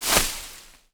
Light  Grass footsteps 5.wav